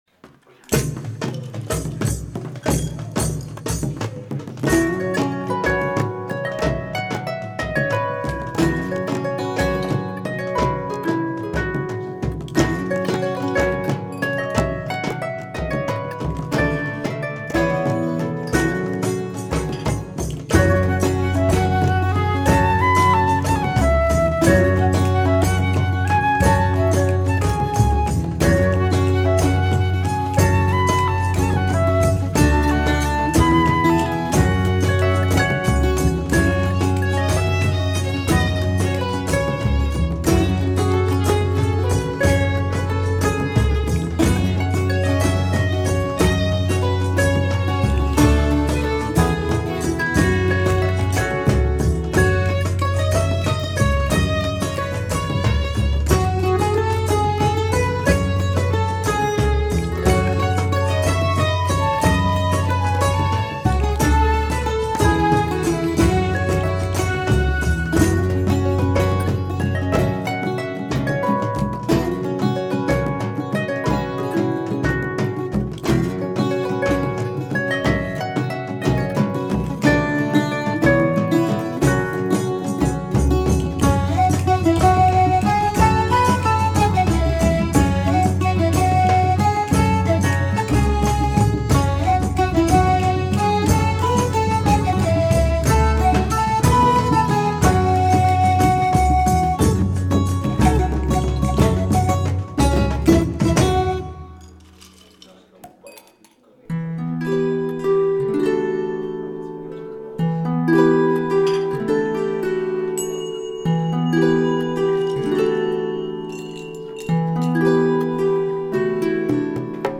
Play tavern music file on login screen